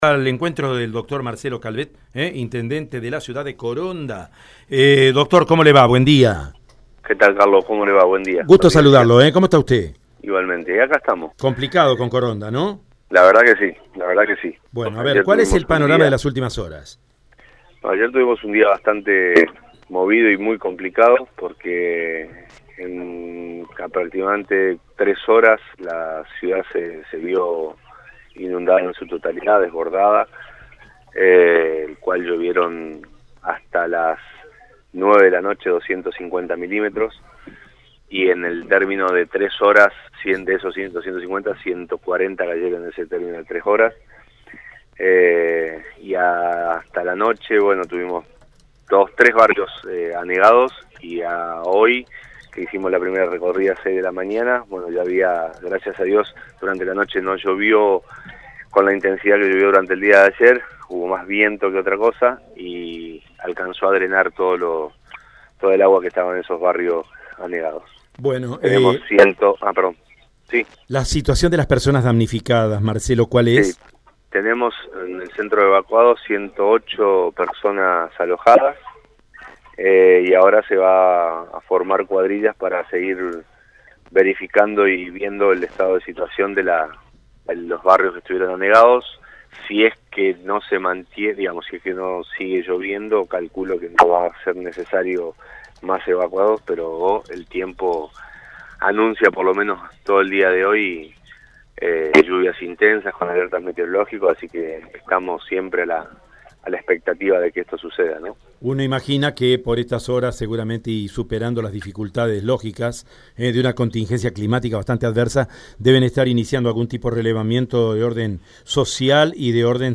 Tras reunirse con la junta de Defensa Civil, el intendente de la ciudad, Marcelo Calvet, confirmó por Aire de Santa Fe que hasta las 21:00 de este domingo eran ya 100 las personas evacuadas.
NOTA-Intendente-Hugo-Marcelo-Calvet-SITUACION-CORONDA.mp3